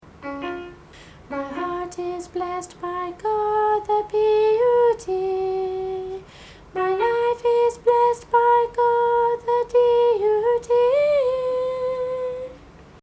See individual song practice recordings below each score.